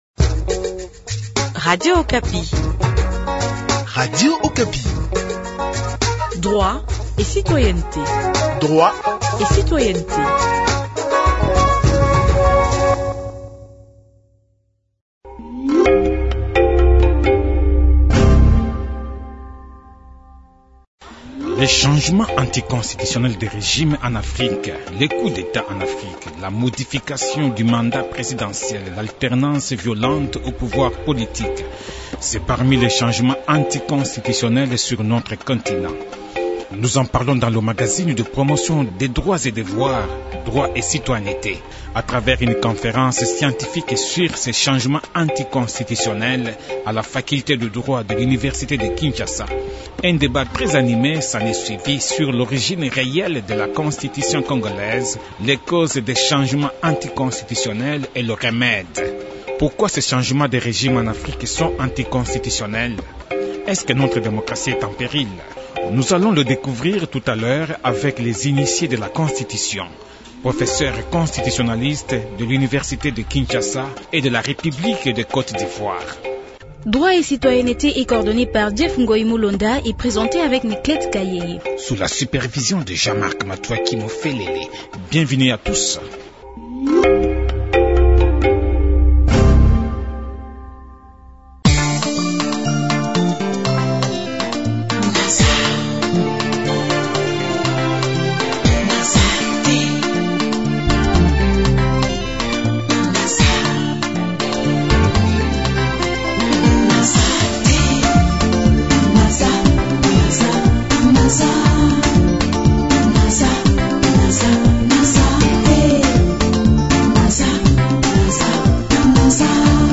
A travers une conférence scientifique sur ces changements anticonstitutionnels à la faculté de droit de l’Université de Kinshasa le vendredi 7 octobre dernier, un débat très animé s’en est suivi sur l’origine réelle de la Constitution congolaise, les causes des changements anticonstitutionnels et le remède.